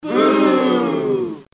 男声起哄呜声音效免费音频素材下载